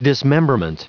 Prononciation du mot dismemberment en anglais (fichier audio)
Prononciation du mot : dismemberment